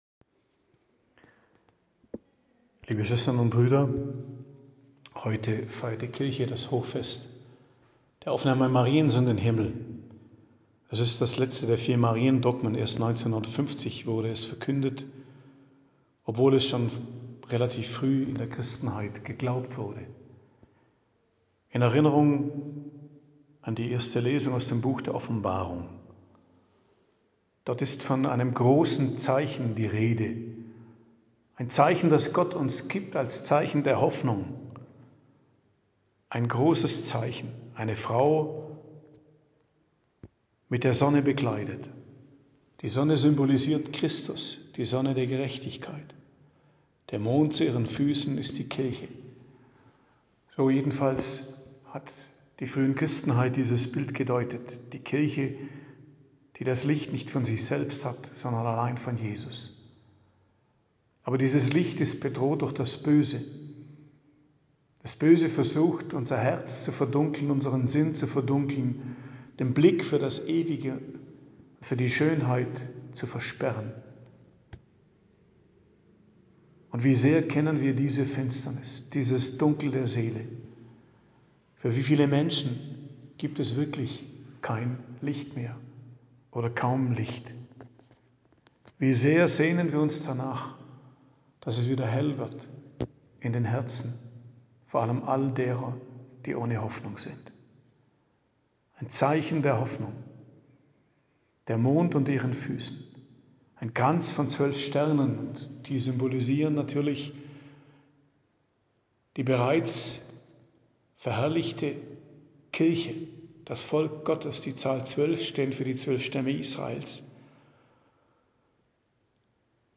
Predigt am Hochfest Mariä Aufnahme in den Himmel, 15.08.2024